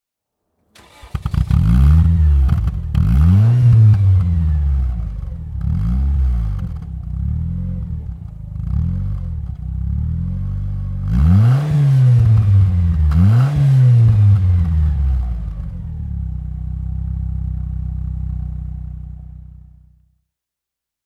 Lotus Elan S2 (1995) - Starten und Leerlauf